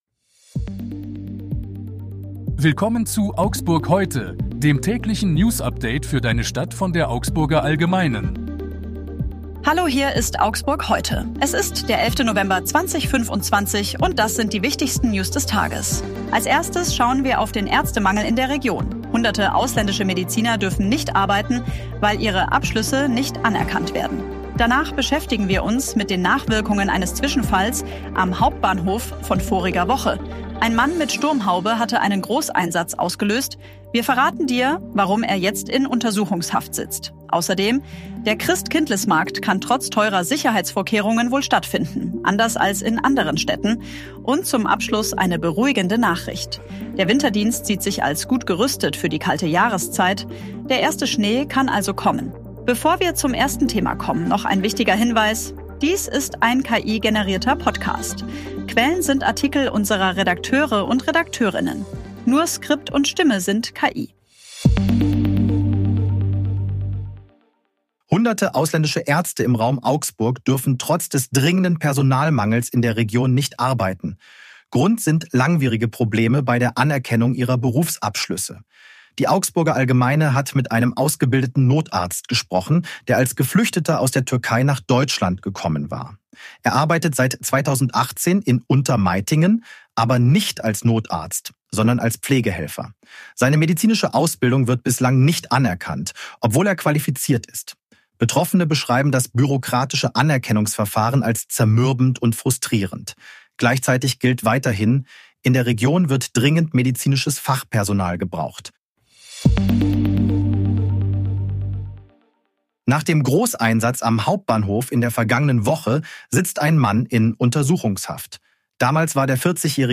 Hier ist das tägliche Newsupdate für deine Stadt.
Redakteurinnen. Nur Skript und Stimme sind KI